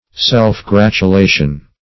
Self-gratulation \Self`-grat`u*la"tion\, n.
self-gratulation.mp3